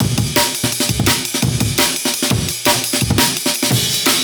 xlr_eq_amen.wav